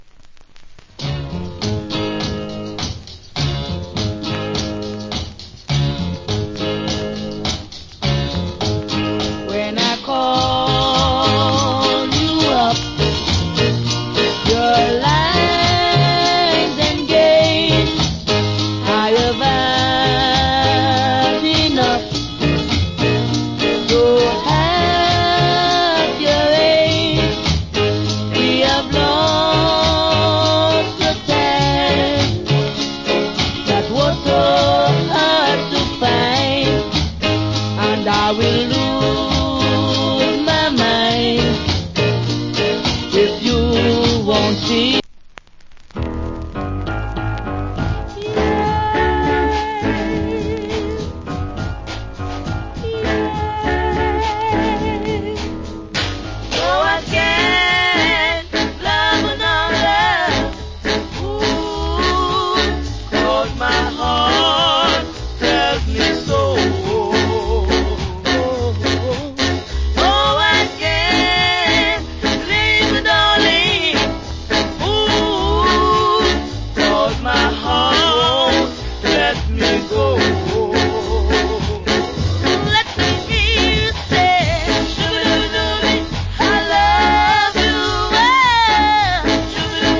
Nice Rock Steady Vocal.